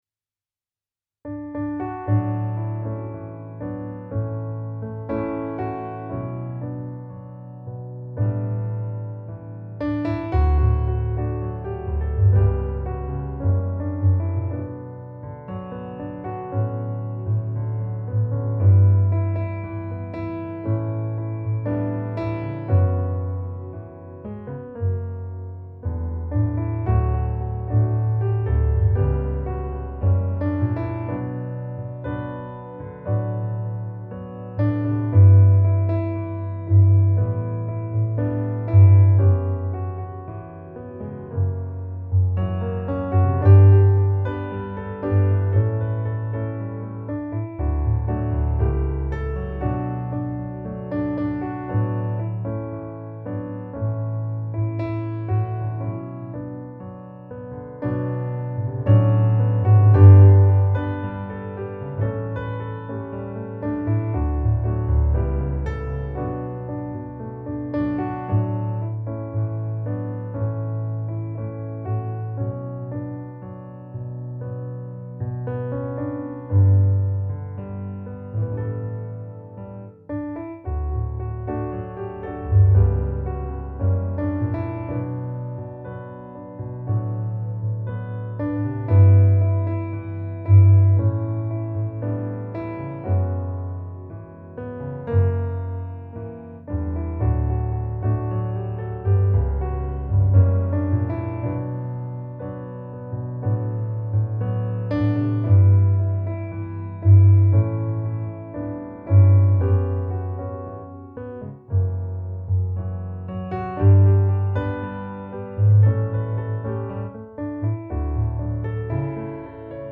musikbakgrund
Gemensam sång
Musikbakgrund Psalm